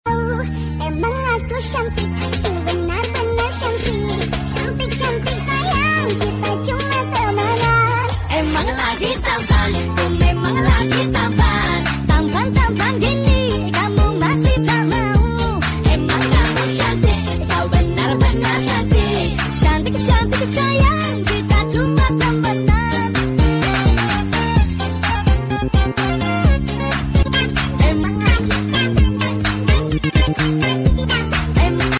SFX萌沙拉卡音效下载
SFX音效